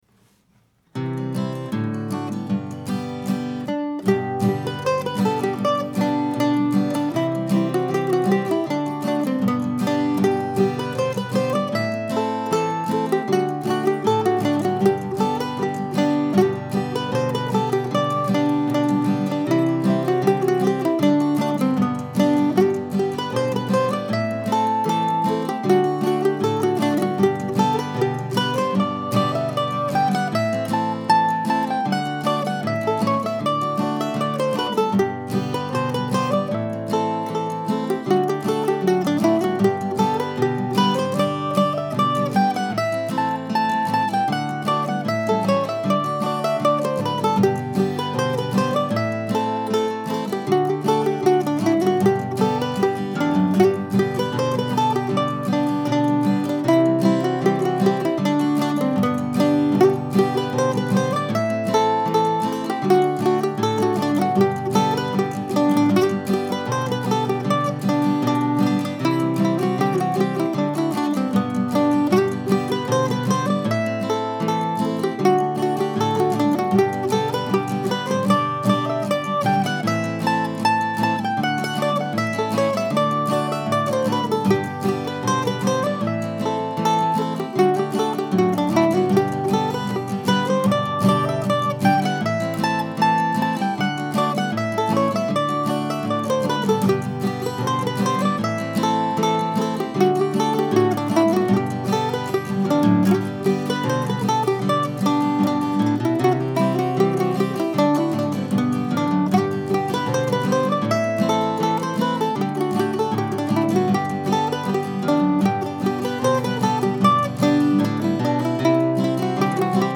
These were both done in bare bones fashion, no harmony parts, no extra instruments. Just guitar chords and mandolin melody, three times through each tune.
I think of it as a potential English Country Dance tune.